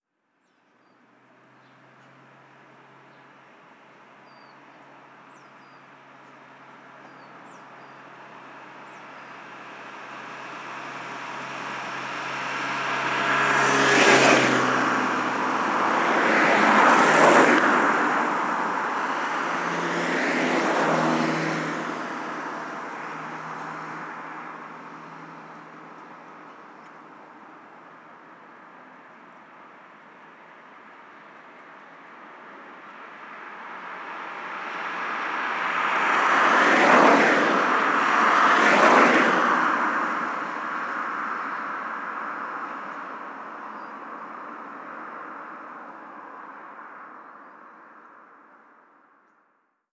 Pasadas de un coche por una carretera
coche
Sonidos: Transportes
Sonidos: Ciudad